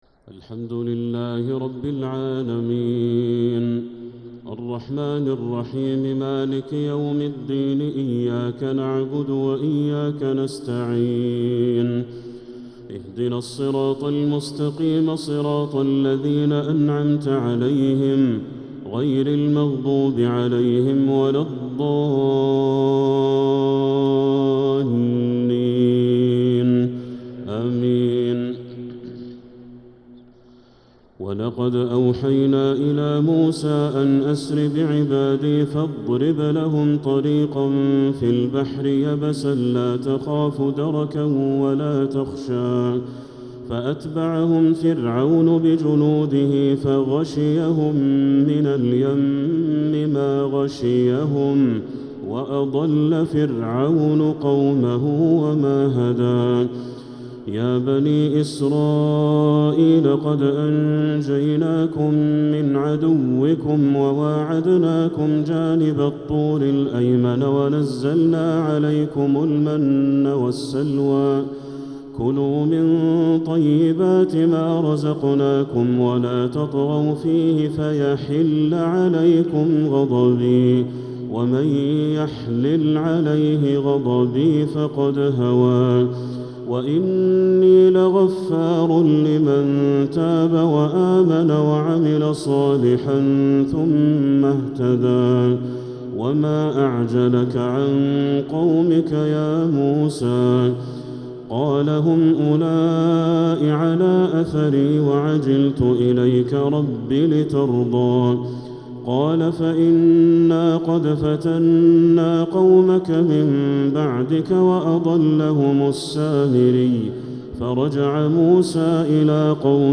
تهجد ليلة 21 رمضان 1446هـ من سورتي طه (77-135) و الأنبياء (1-47) | Tahajjud 21st night Ramadan 1446H Surah Taha and Al-Anbiya > تراويح الحرم المكي عام 1446 🕋 > التراويح - تلاوات الحرمين